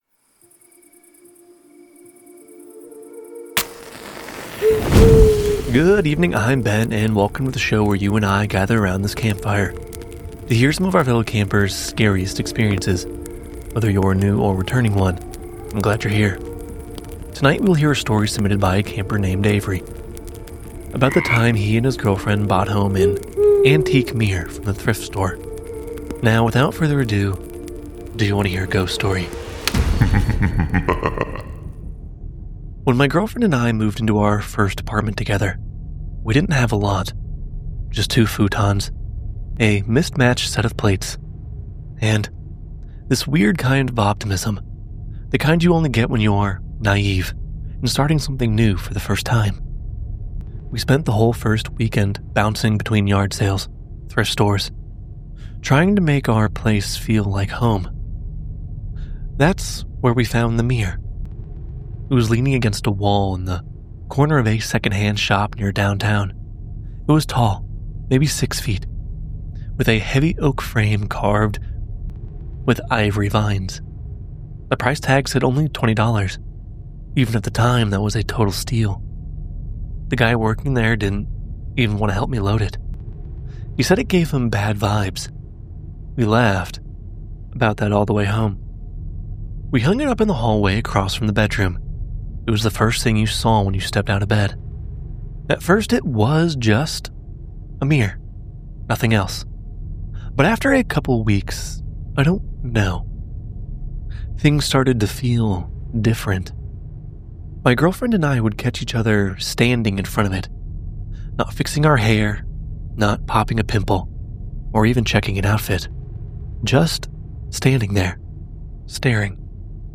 Narrated by:
Sound Design by: